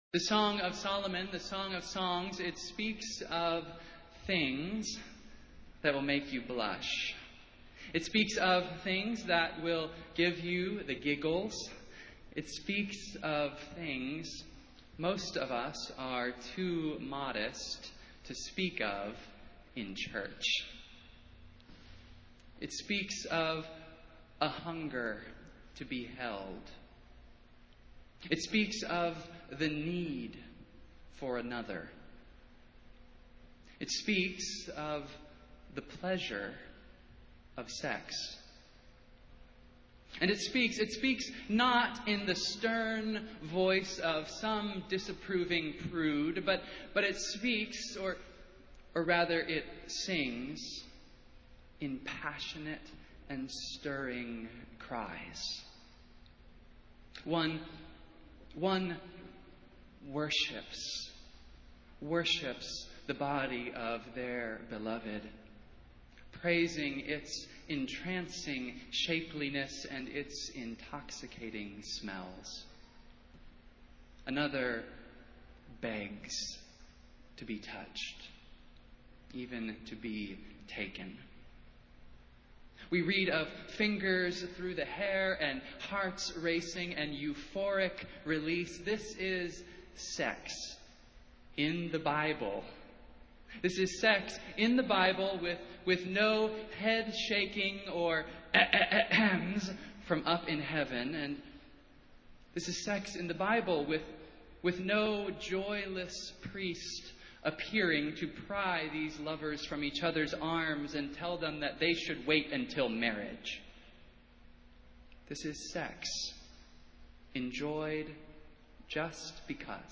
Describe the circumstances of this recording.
Festival Worship - Fourteenth Sunday after Pentecost